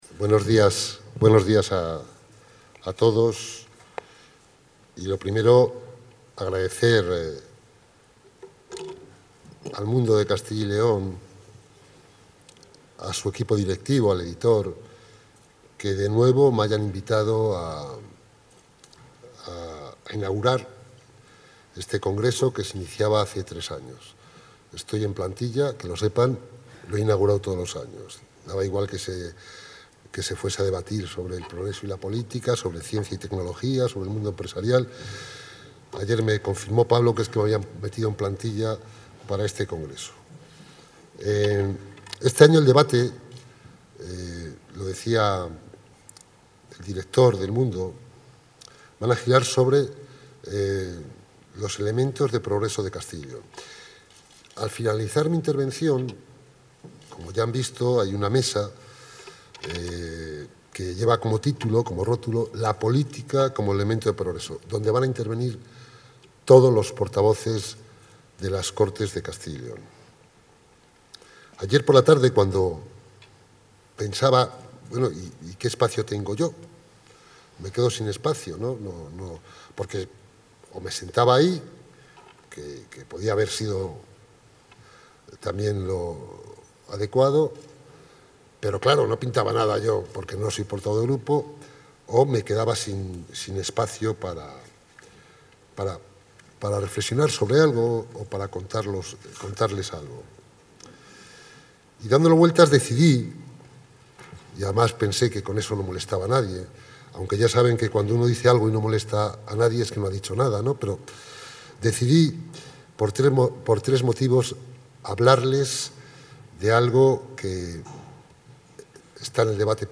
El vicepresidente y consejero de la Presidencia, José Antonio de Santiago-Juárez, ha inaugurado hoy el Congreso SomosCyL, organizado por Diario de Castilla y León-El Mundo.